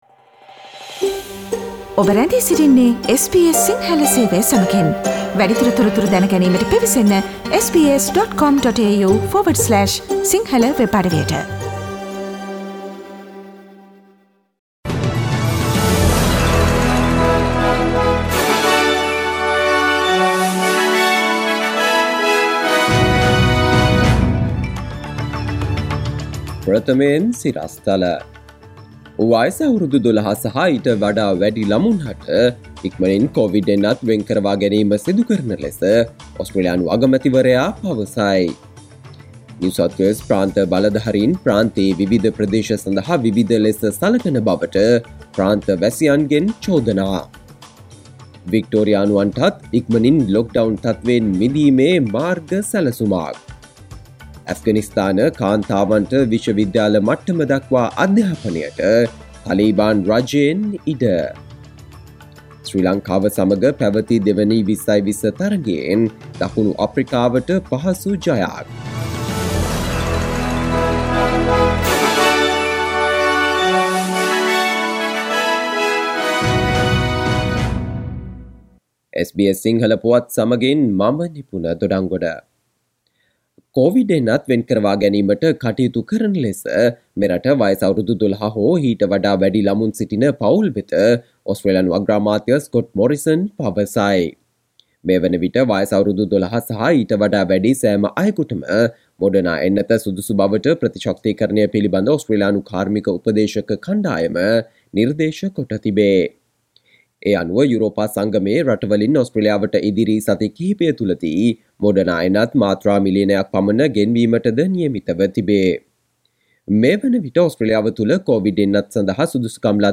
සවන්දෙන්න 2021 සැප්තැම්බර්13 වන සඳුදා SBS සිංහල ගුවන්විදුලියේ ප්‍රවෘත්ති ප්‍රකාශයට...